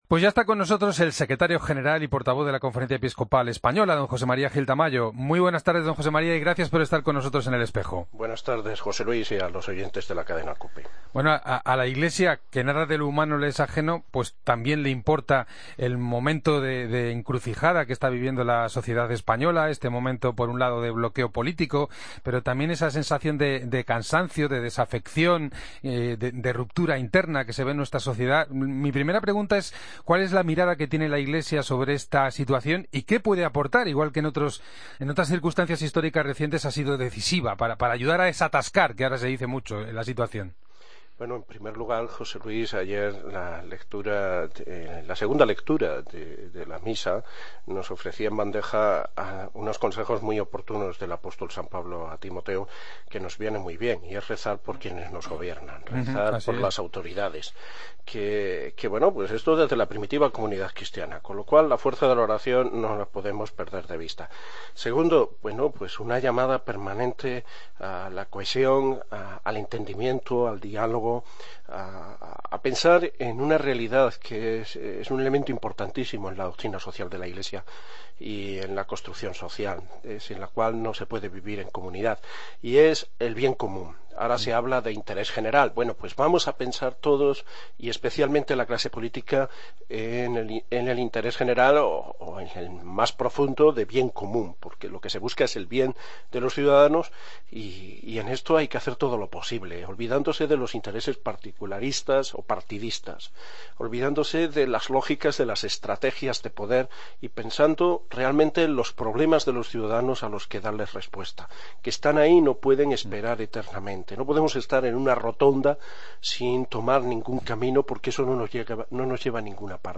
Escucha al portavoz de la CEE, José María Gil Tamayo, en 'El Espejo'